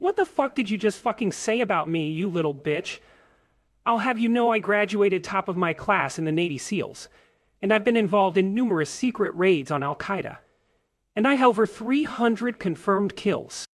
sample-speaker.wav